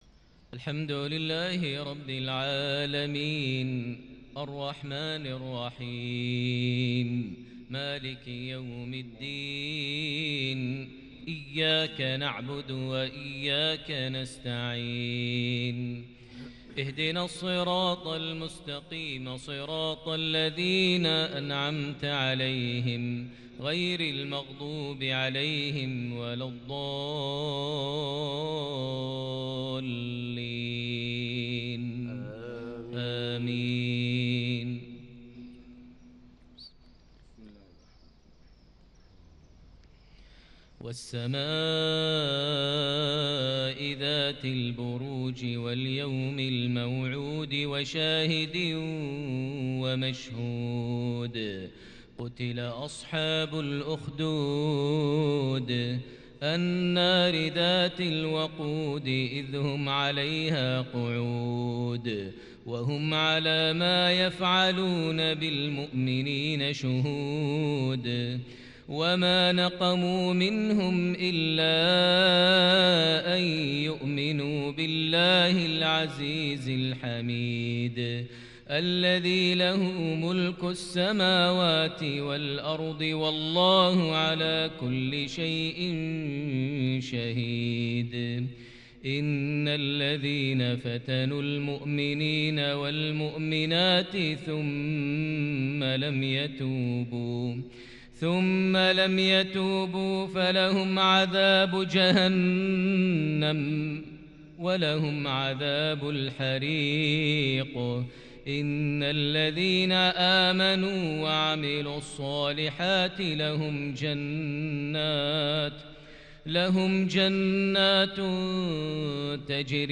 مغربية مباركة لسورة البروج | غرة شهر شعبان 1442هـ > 1442 هـ > الفروض - تلاوات ماهر المعيقلي